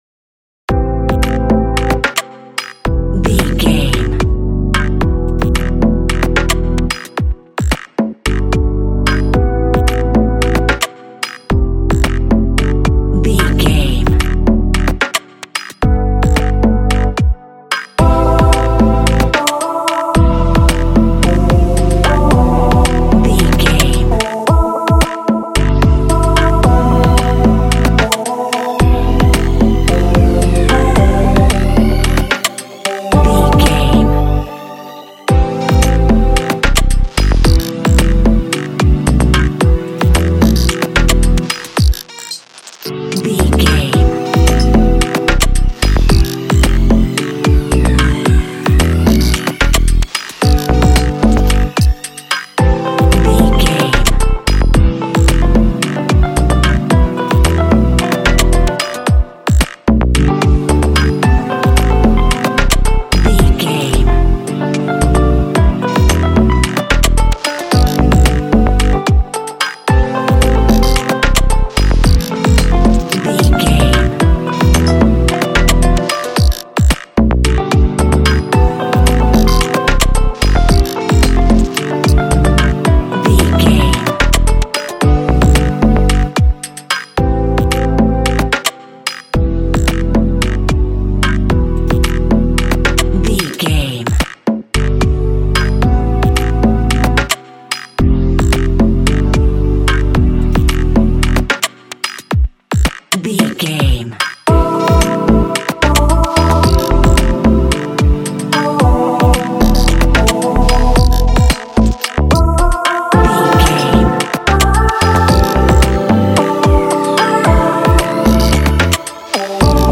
Ionian/Major
hypnotic
atmospheric
mysterious